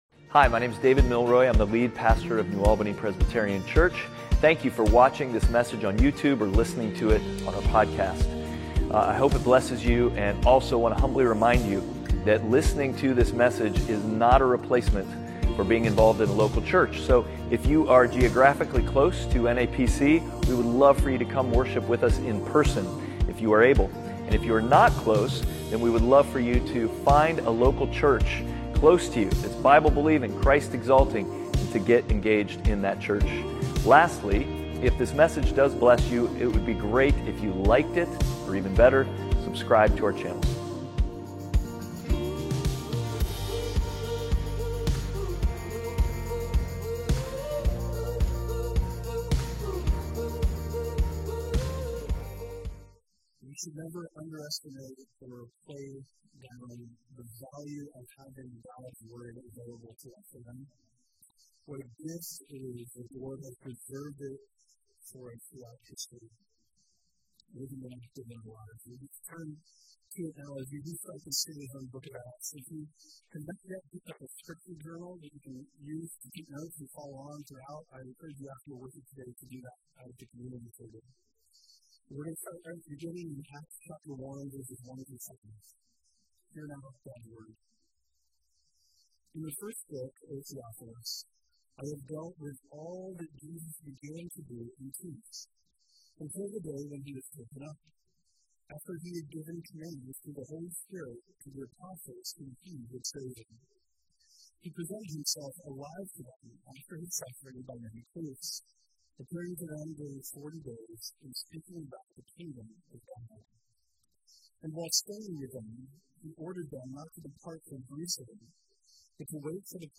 Outward Passage: Acts 1:1-5 Service Type: Sunday Worship « Achored in Truth